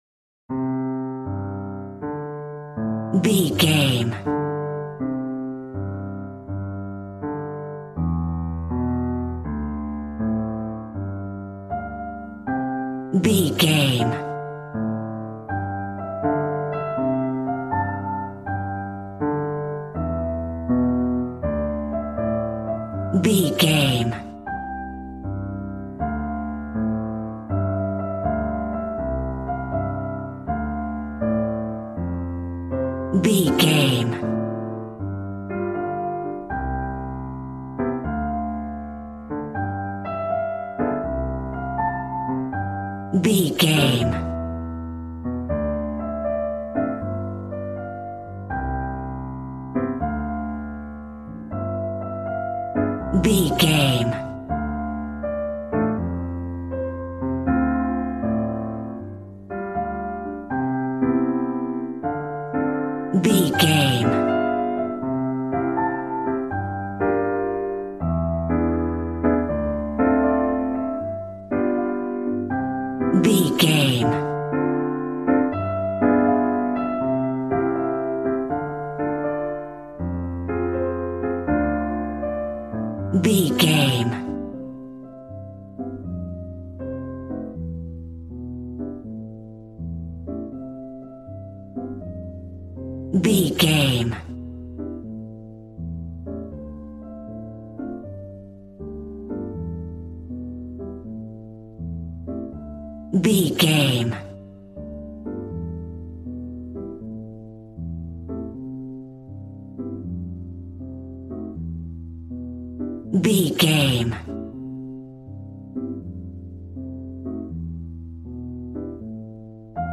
Smooth jazz piano mixed with jazz bass and cool jazz drums.,
Aeolian/Minor
piano